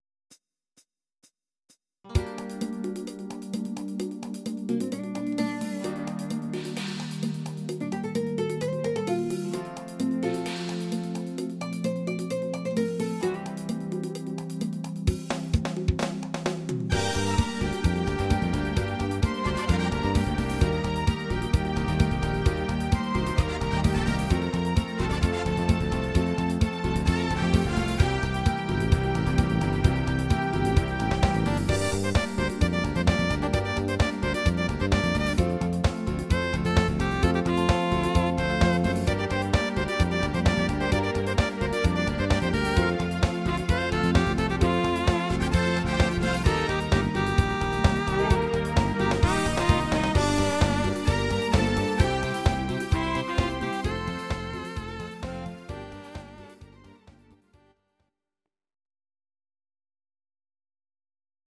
These are MP3 versions of our MIDI file catalogue.
Please note: no vocals and no karaoke included.
(live version)